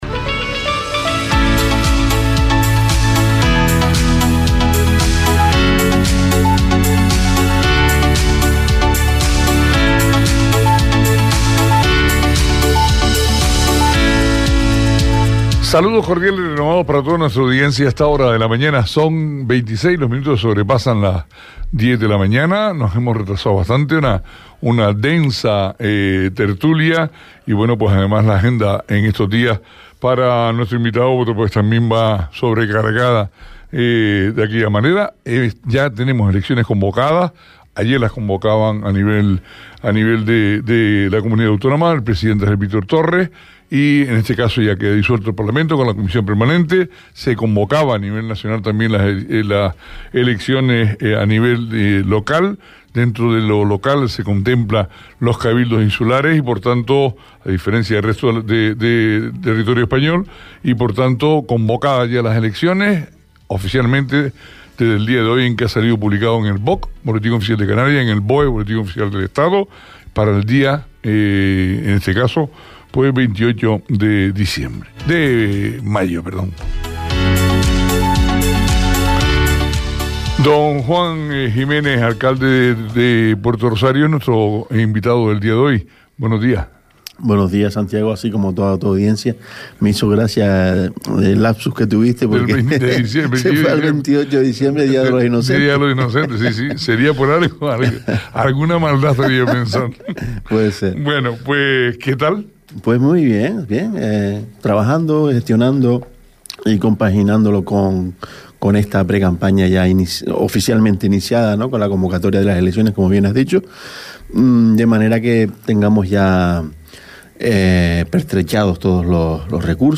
Entrevista a Juan Jiménez, alcalde de Puerto del Rosario - 04.04.23 - Radio Sintonía
Entrevista a Juan Jiménez, alcalde de Puerto del Rosario.
Entrevistas